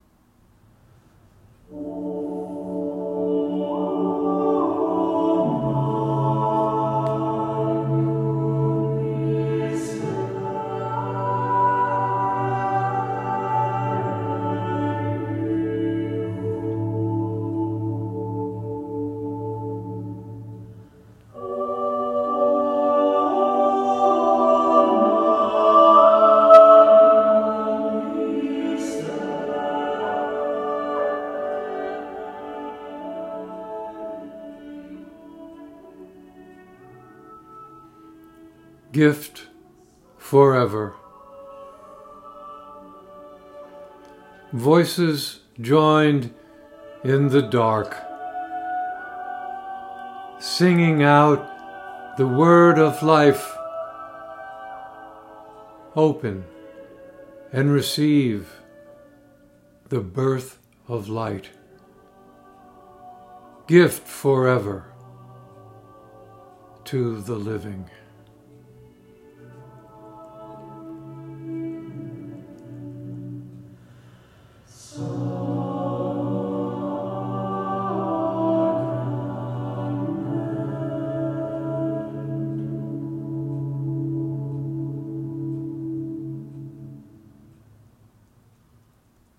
Reading of “Gift Forever” with music by Voces8
Beautiful music to surround the birth of light.